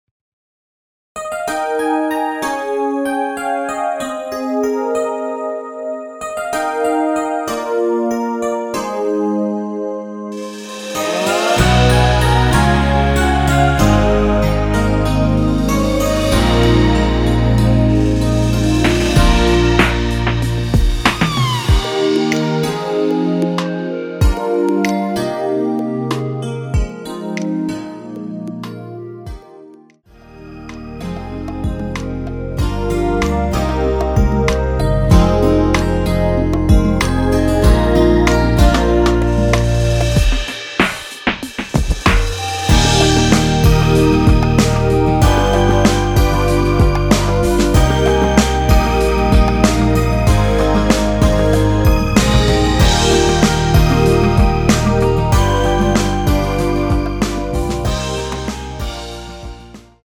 원키에서(-1)내린 멜로디 포함된 MR입니다.(미리듣기 참고)
Eb
앞부분30초, 뒷부분30초씩 편집해서 올려 드리고 있습니다.
중간에 음이 끈어지고 다시 나오는 이유는